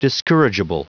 Prononciation du mot discourageable en anglais (fichier audio)
Prononciation du mot : discourageable